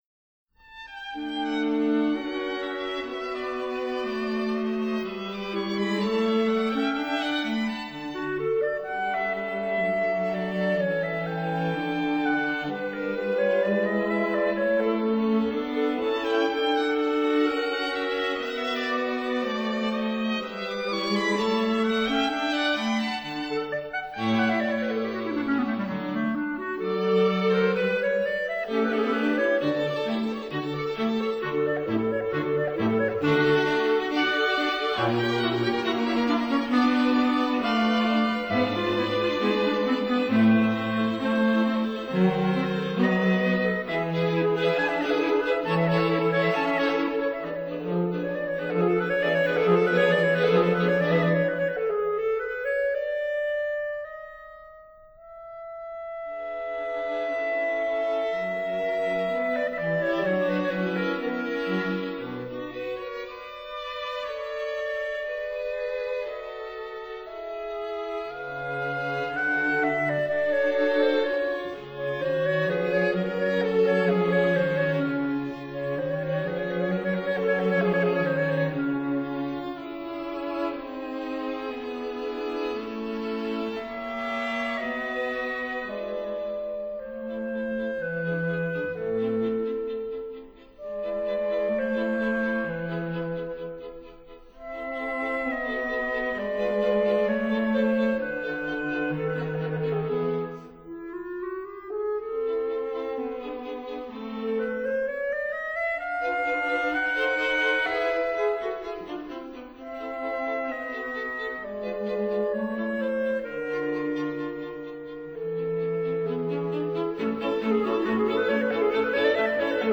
clarinet
violin
viola
cello